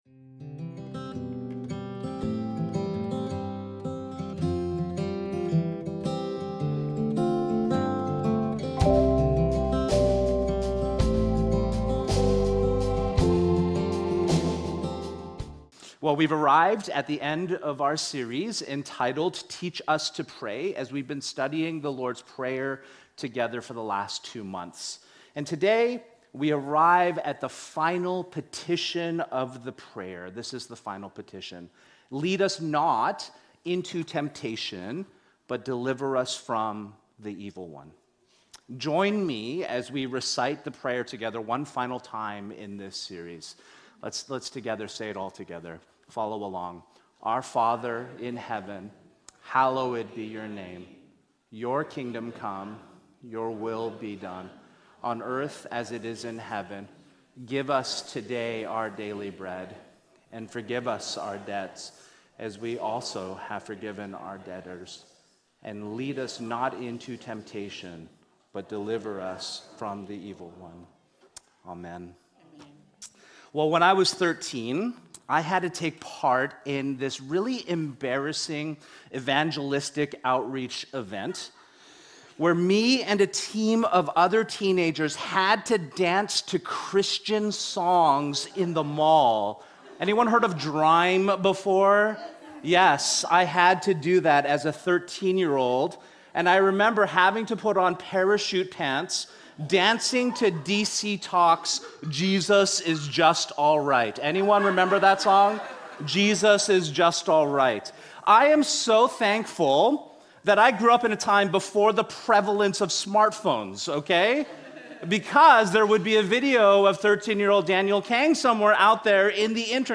MESSAGES | Redemption Church